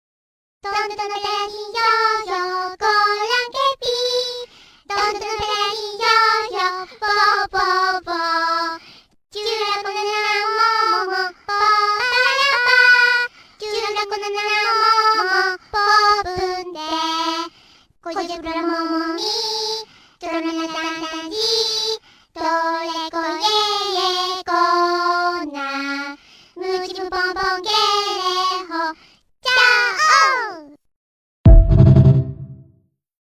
Just a video of Chaos singing